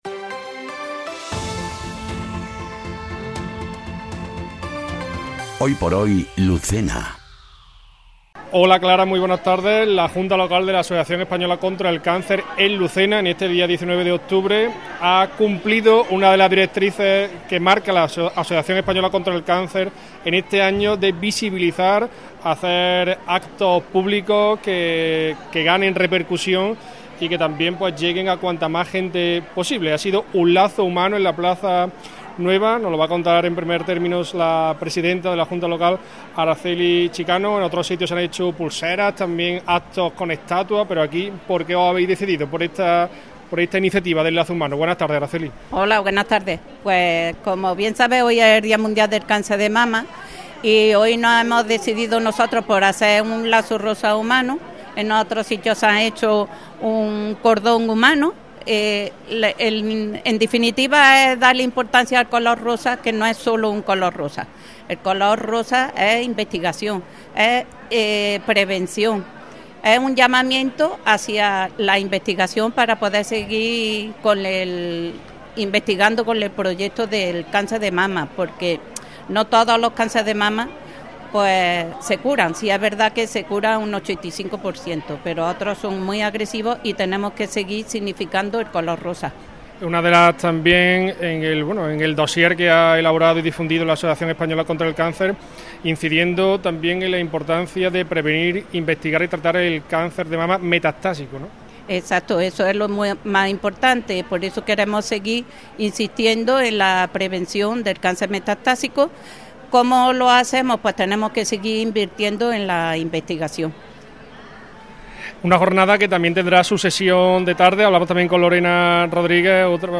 ENTREVISTA | AECC Lucena, Día Mundial Contra el Cáncer de Mama